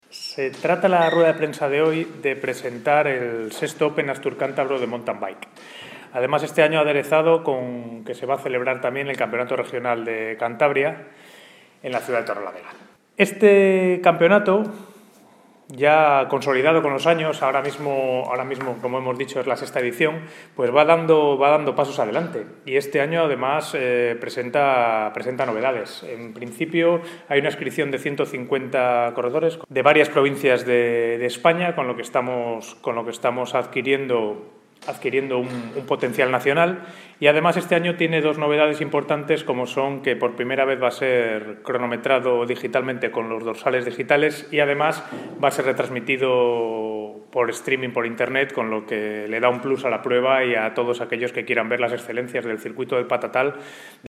Presentaci?n del Campeonato de MTB
Jes?s S?nchez, concejal de Deportes